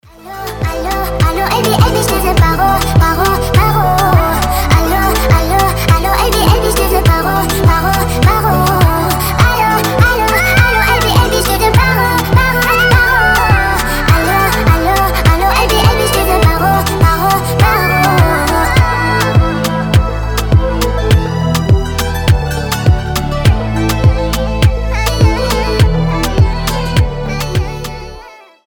Танцевальные рингтоны
спокойные женский голос